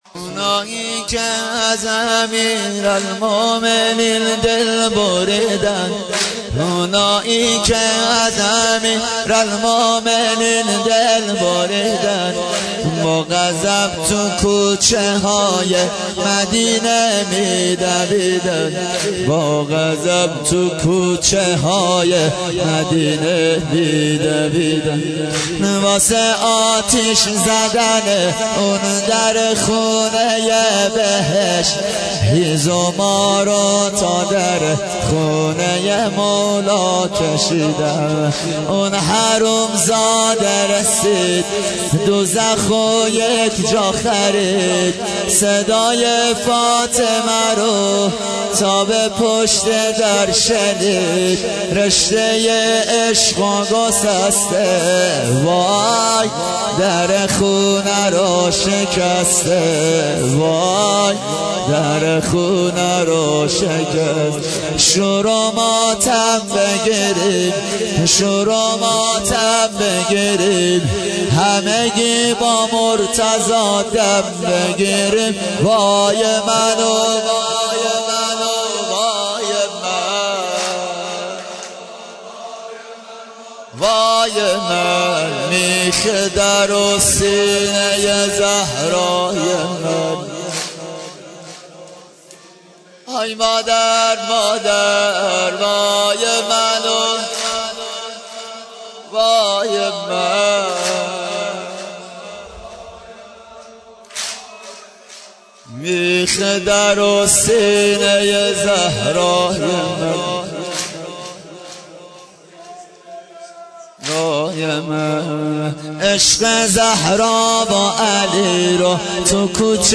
دانلود مداحی میخ در و سینه زهرای من - دانلود ریمیکس و آهنگ جدید
سینه زنی در شهادت بانوی دوعالم حضرت زهرا(س)